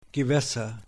Ääntäminen
Synonyymit sjö Ääntäminen : IPA: [ˈvat.ɛn] Tuntematon aksentti: IPA: /ˈvatː.ɛn/ Haettu sana löytyi näillä lähdekielillä: ruotsi Käännös Ääninäyte Substantiivit 1. Wasser {das} 2.